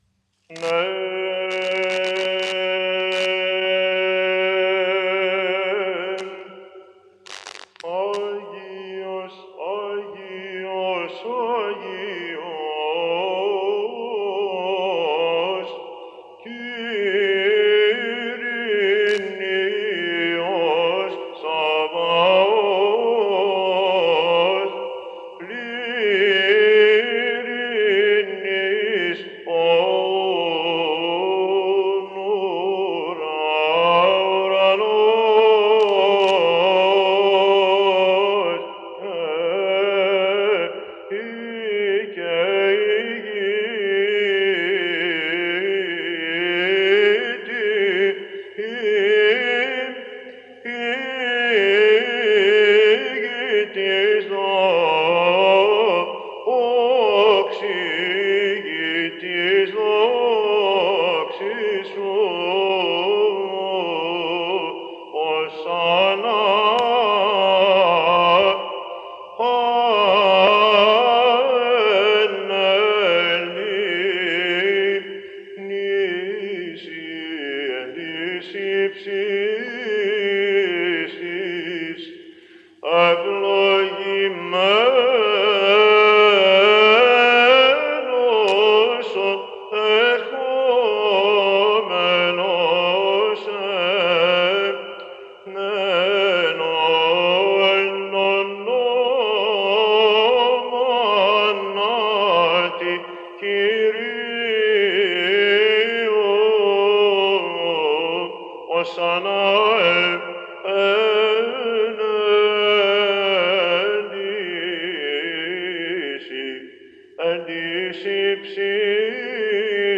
Λειτουργικὰ Θείας Λειτουργίας Μεγάλου Βασιλείου, Στανίτσας — ἔτος 1960
LEITOURGIKA_MEG_BASILEIOU_CONSTANTINOPLE_1960.mp3